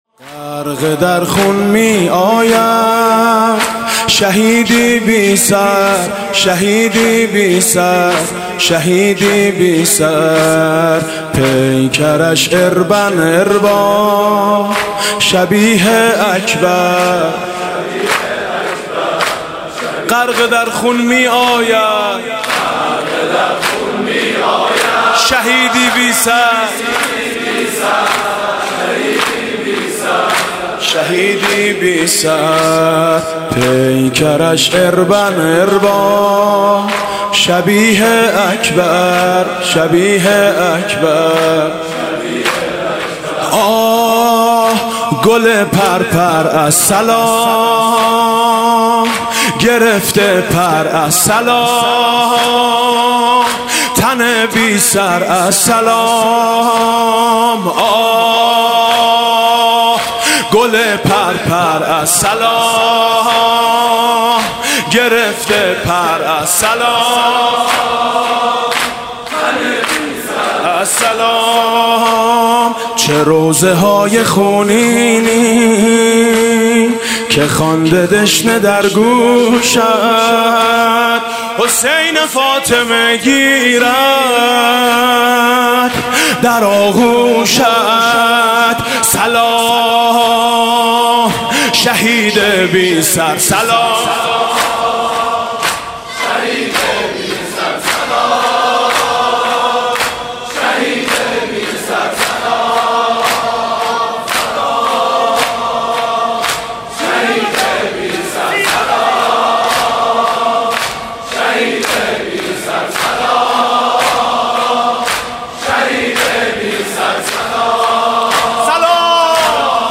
شب ششم محرم
دانشگاه امام صادق تهران